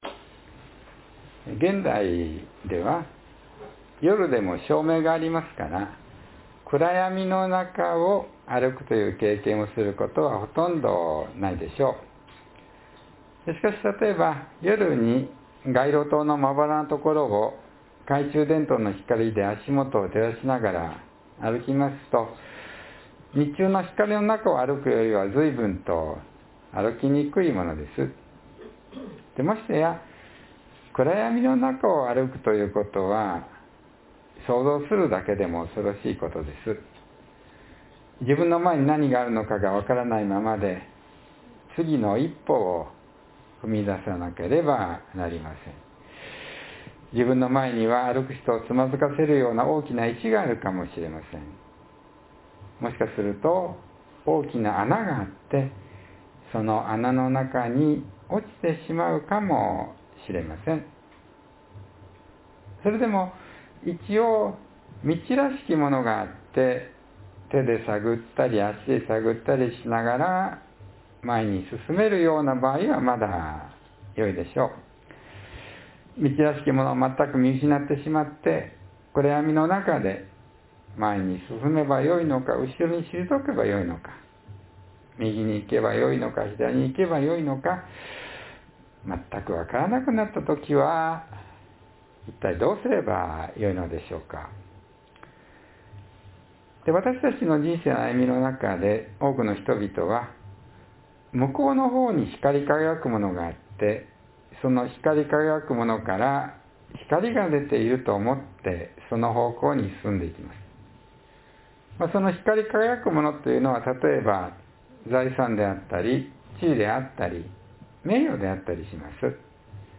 （2月8日の説教より）